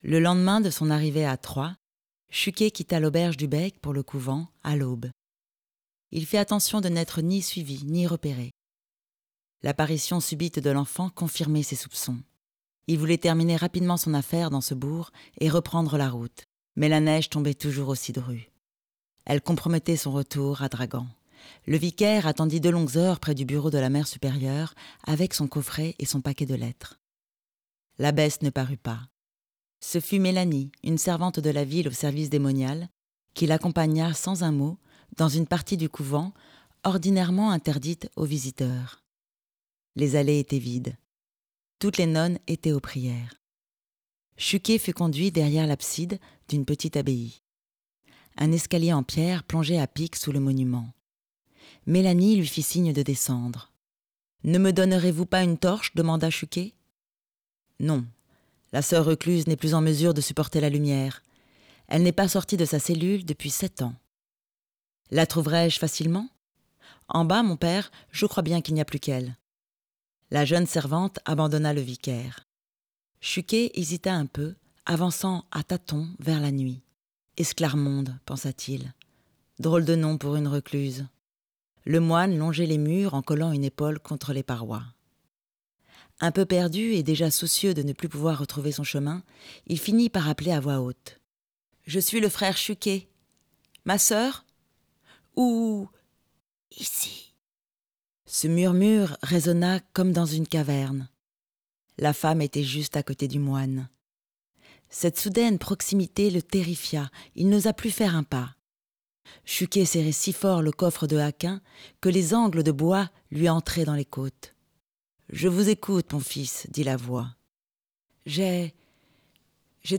Livre lu - Pardonnez vos offenses, Romain Sardou